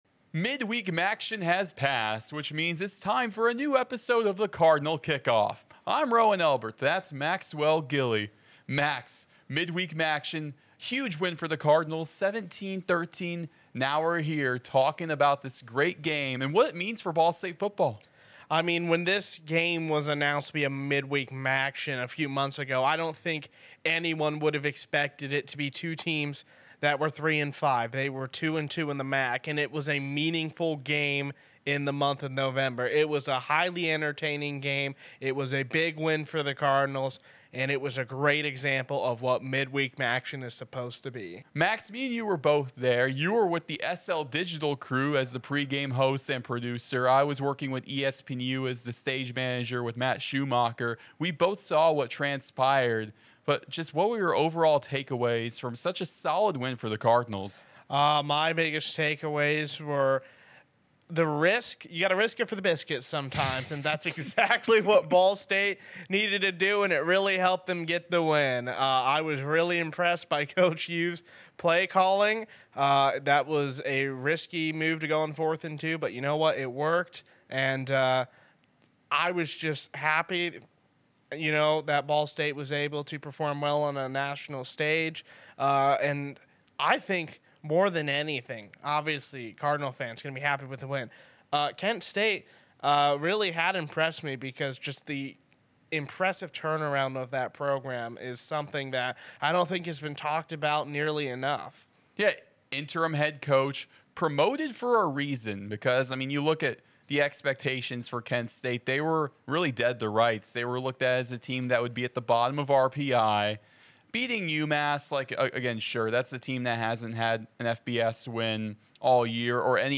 Each week go inside Ball State Football with exclusive interviews, feature stories, and discussion.